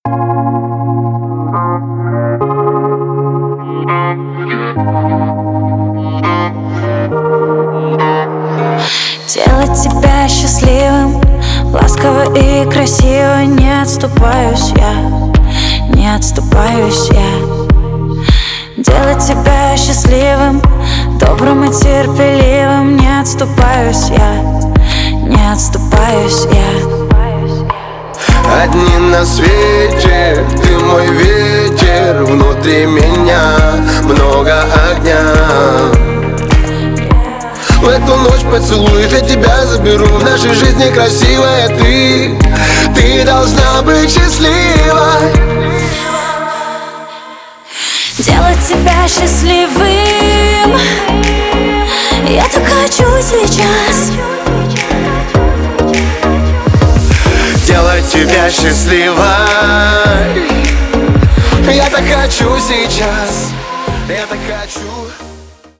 • Качество: 320, Stereo
поп
мужской вокал
женский вокал
чувственные